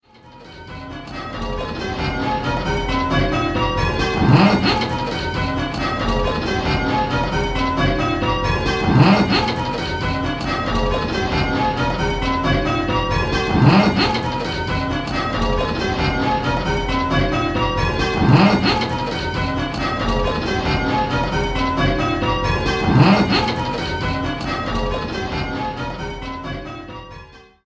Where's the Treble: 10-Bell - Pebworth Bells